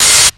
歪曲的Drumsamples from Proteus F X " dist base 02
描述：来自Proteus FX的踢球样本通过Boss GX700进行了扭曲
标签： 节拍 扭曲 变形 FX 变形杆菌 样品 垃圾桶
声道立体声